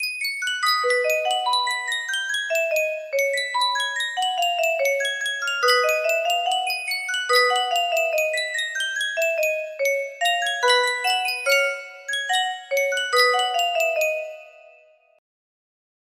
Sankyo Music Box - Sweet Adeline 7T music box melody
Full range 60